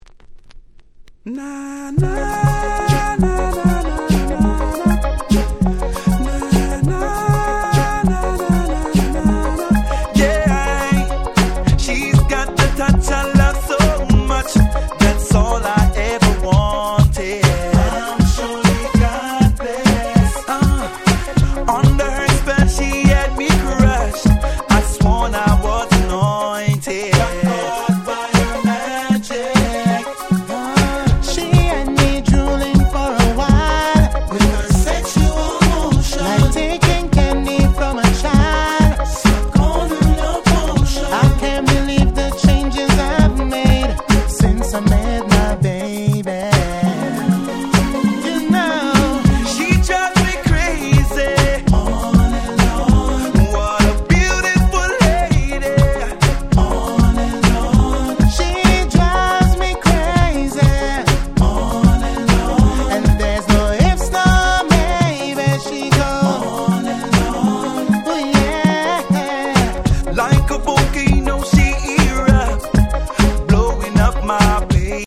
04' Smash Hit Reggae / Soca !!
こちらもGroovyで最高、文句無し！
レゲエ ソカ 00's キャッチー系